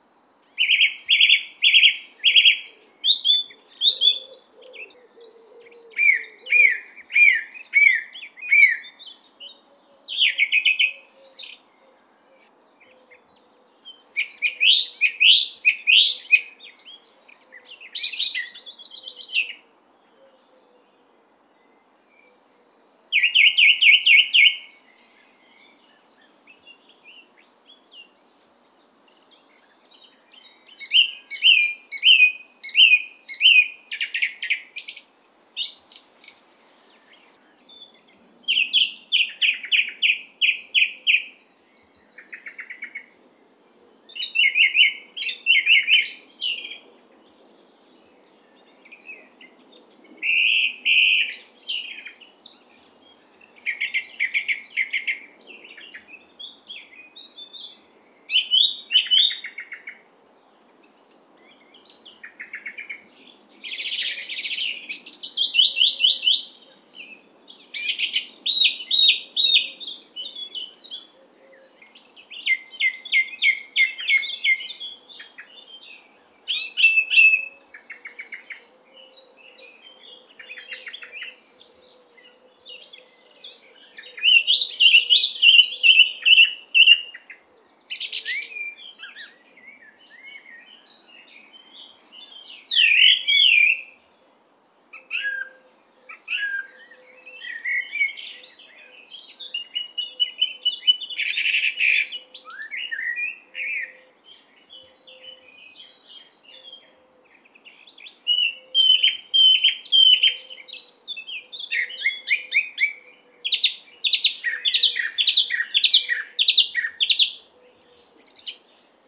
for piano solo
piano.